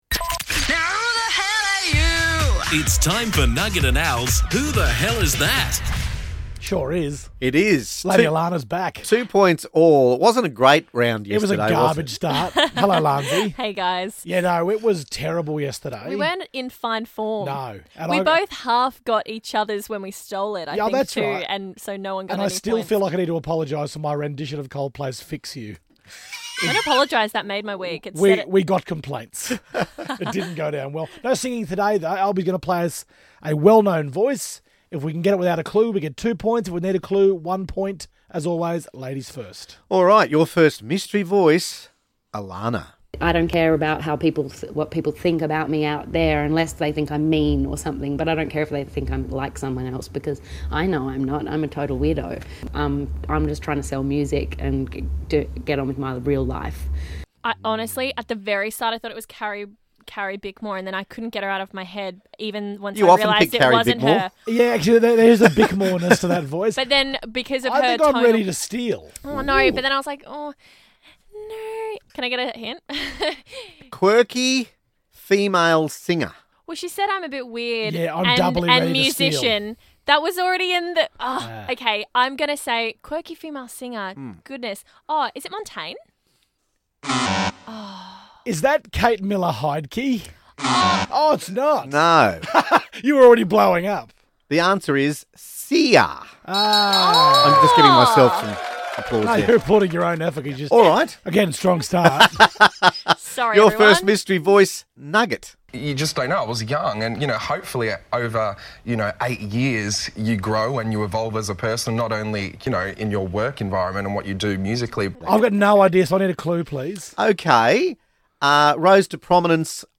Here's today's round of "Who The Hell Is That"?......guess the celebrity mystery voices....